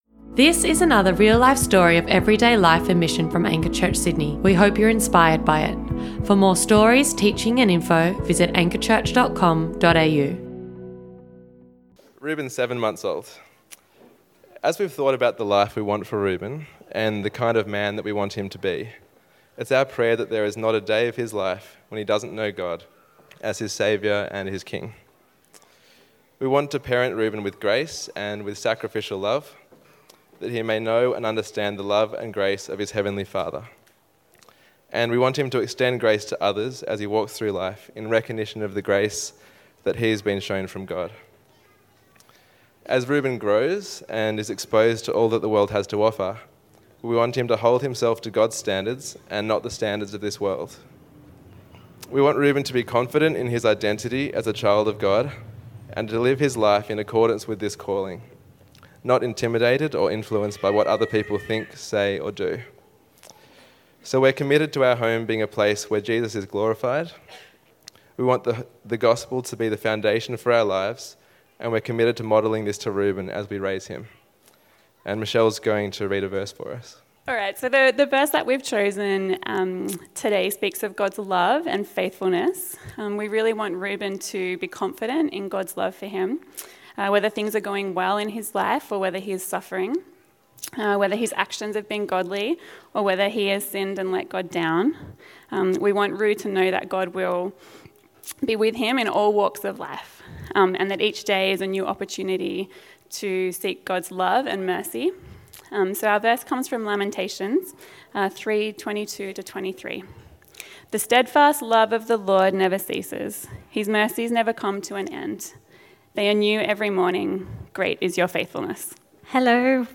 What a special day we had on Baby Dedication Sunday celebrating the smallest members of the Anchor Family! Listen in to the commitments our parents made to raise their kids to know and love Jesus.